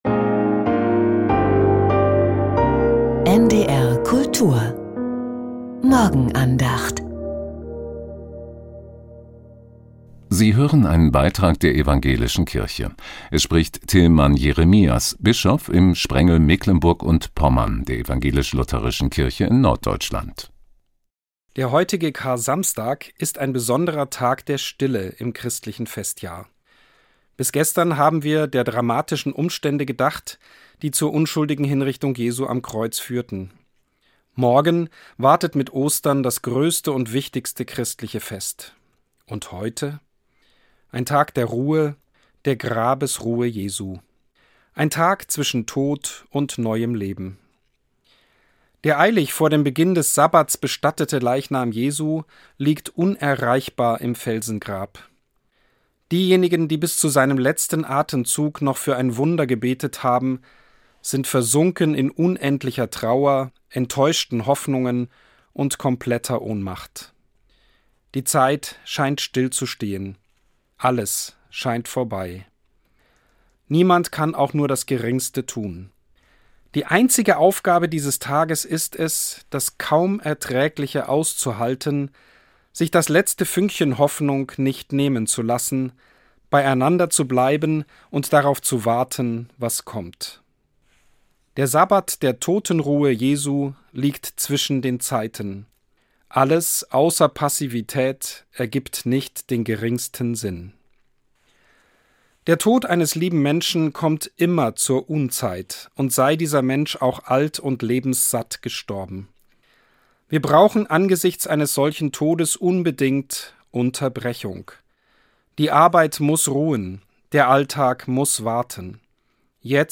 Die Morgenandacht bei NDR Kultur